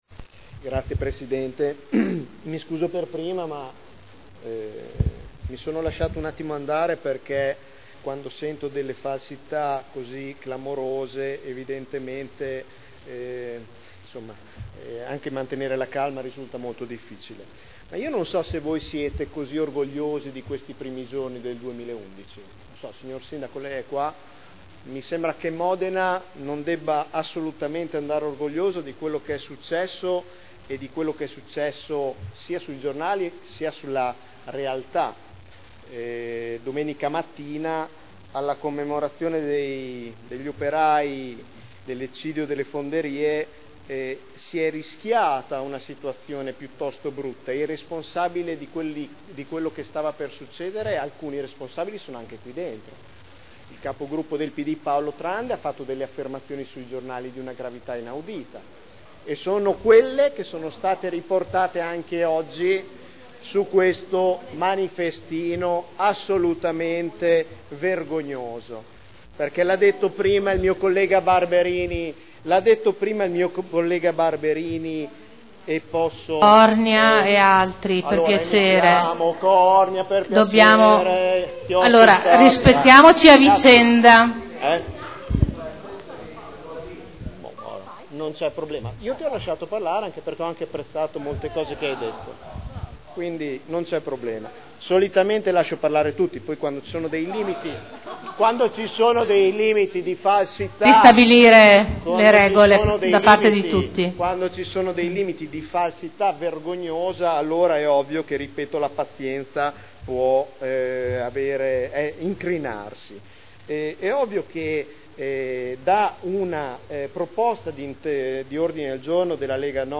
Nicola Rossi — Sito Audio Consiglio Comunale